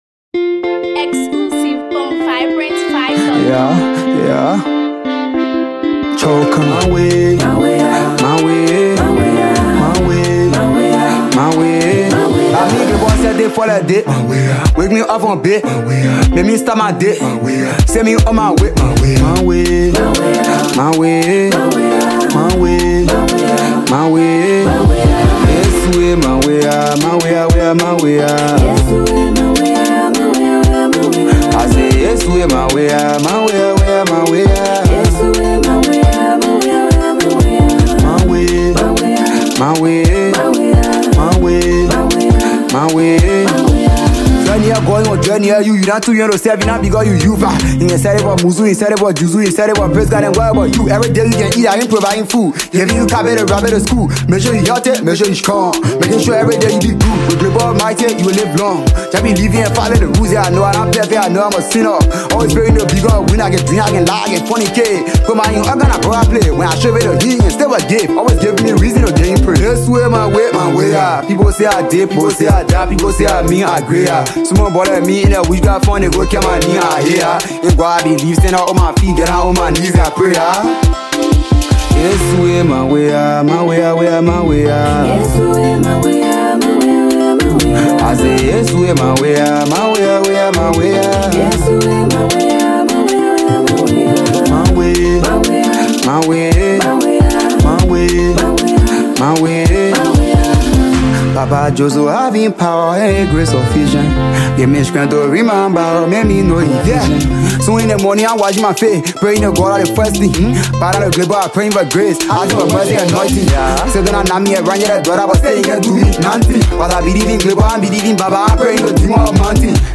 dynamic rhythm and infectious melodies
Afrobeat vibes with modern musical elements